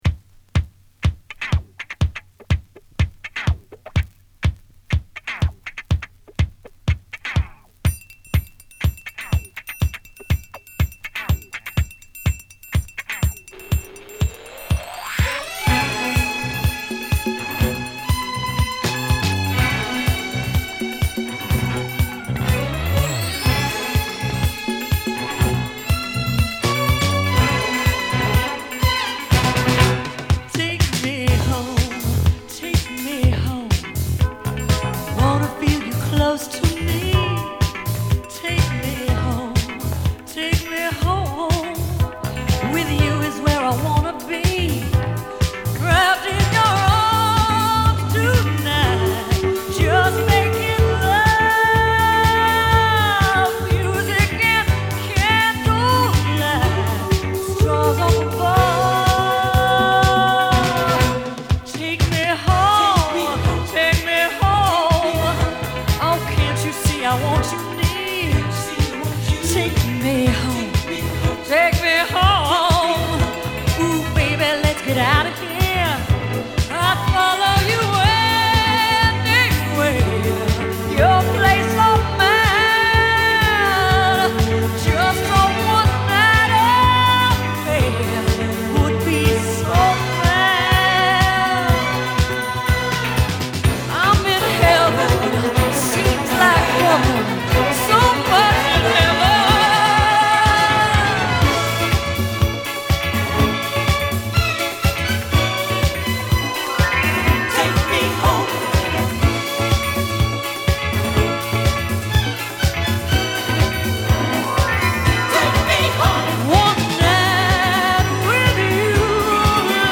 mixed as a continuous groove